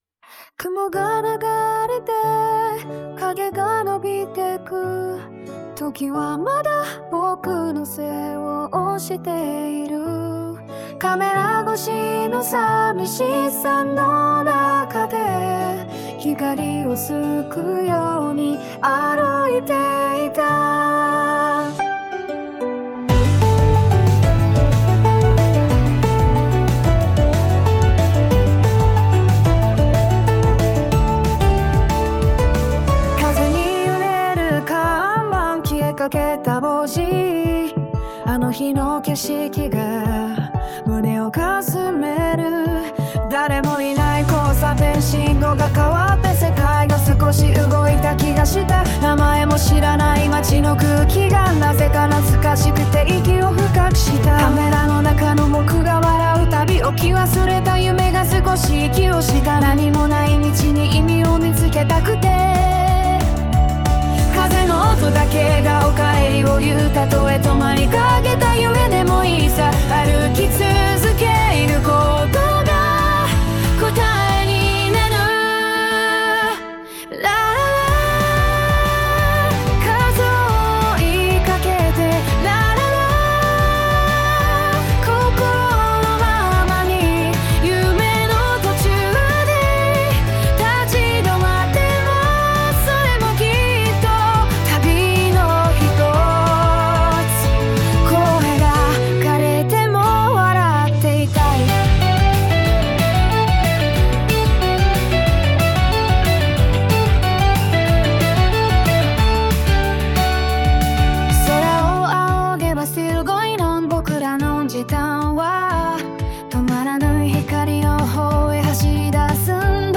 このサイトの音楽は、AI作曲ツールなどを使って制作しています。